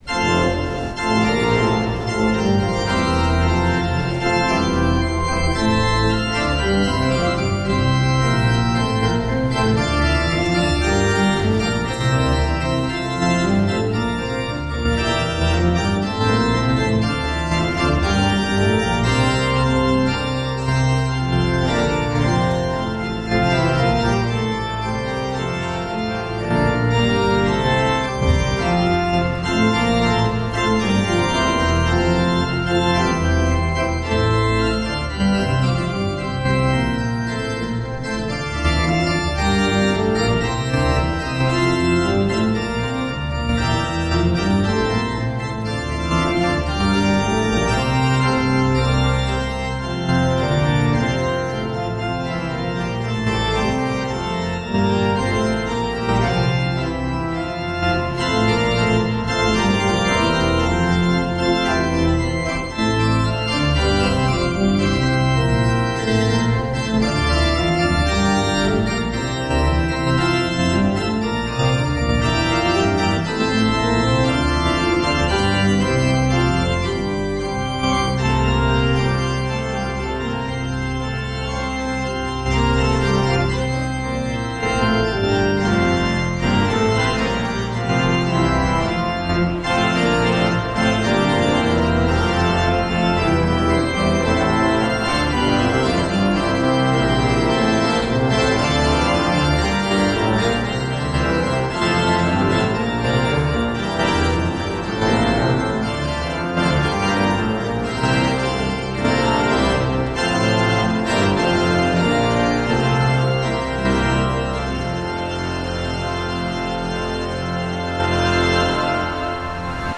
Hear the Bible Study from St. Paul's Lutheran Church in Des Peres, MO, from March 1, 2026.